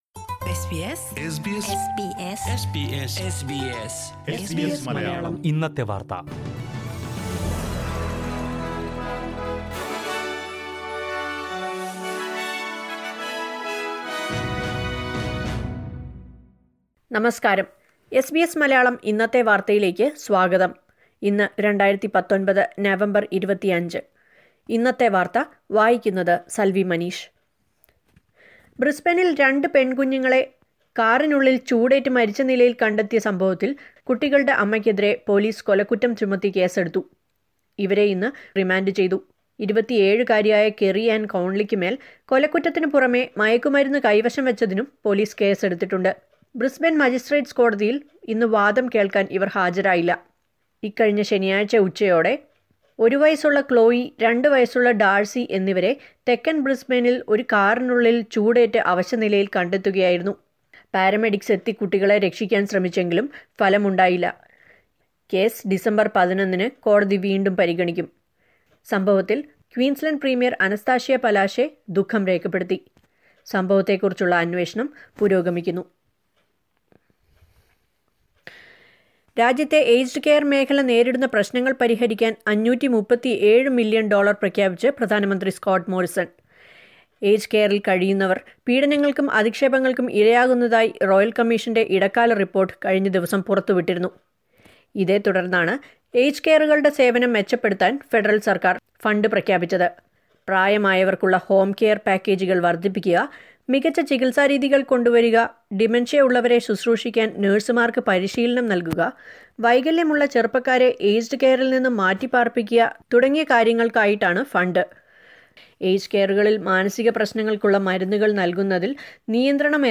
news_nov_25.mp3